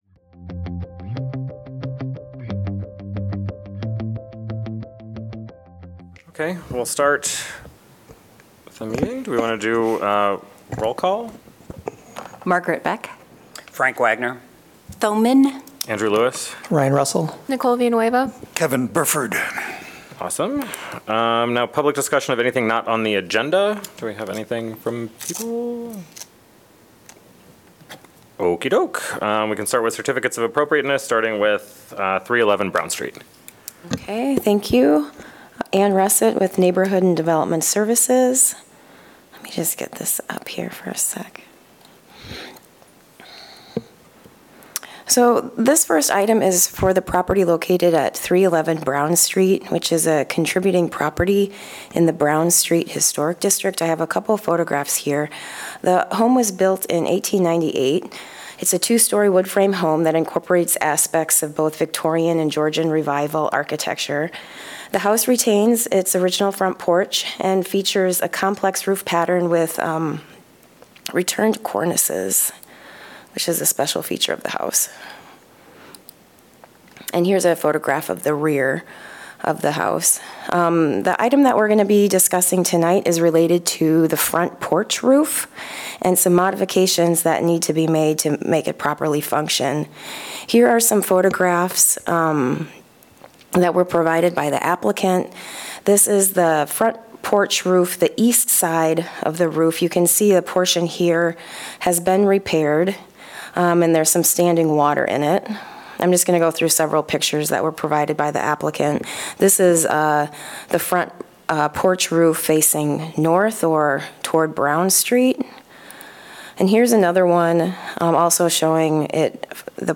Regular meeting of the Iowa City Historic Preservation Commission.